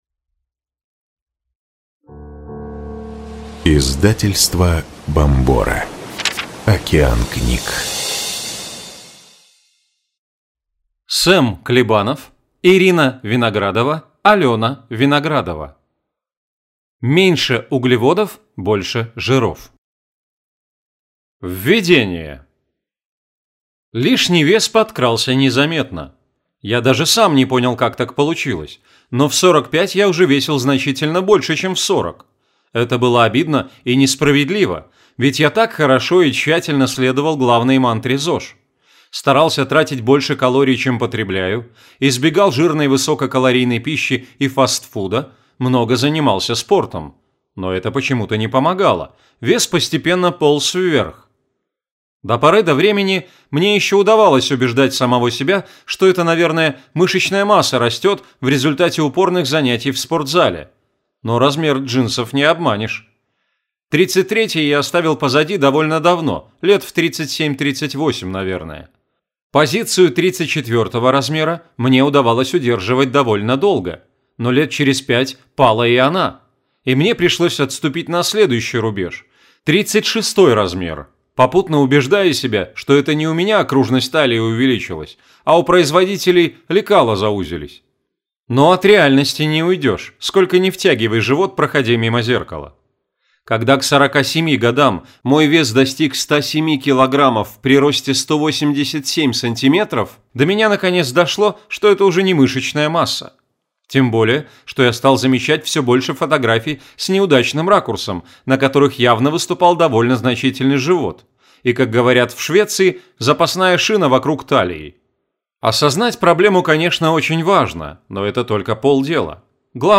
Аудиокнига Меньше углеводов – больше жиров! Полное руководство по кето/LCHF с рецептами | Библиотека аудиокниг